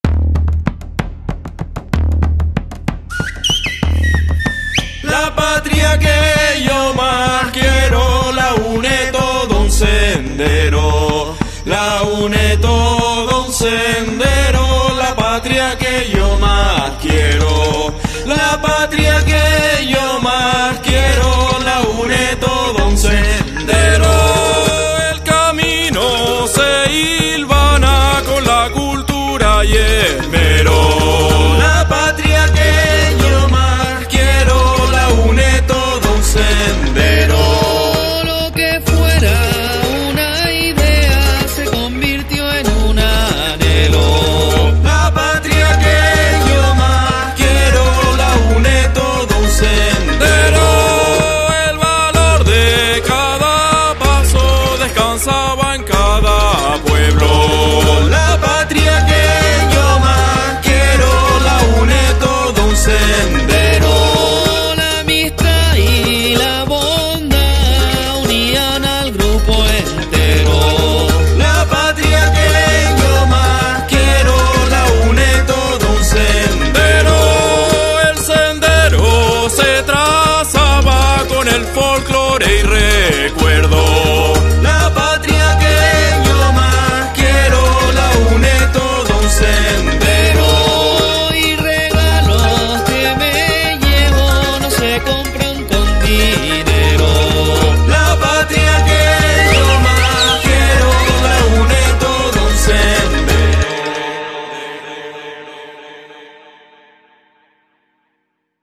Es un romance tradicional al estilo de la isla de El Hierro.